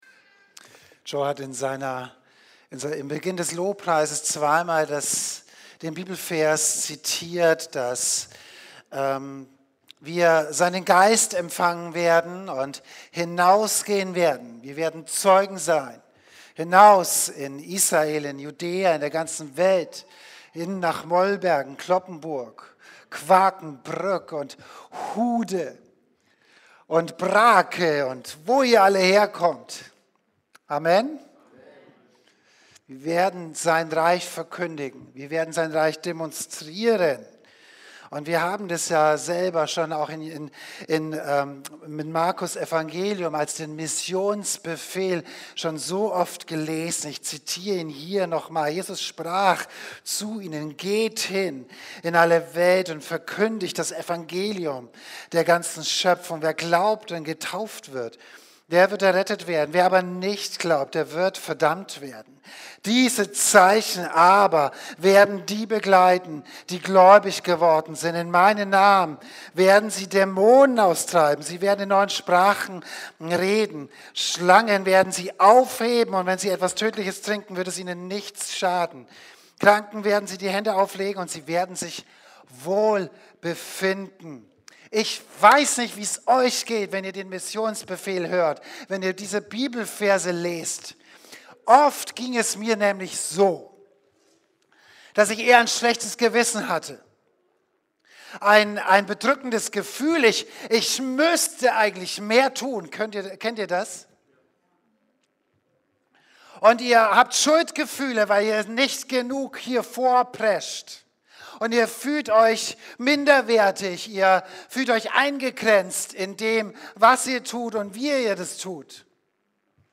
Predigten | Oase Christengemeinde
Dienstart: Gottesdienst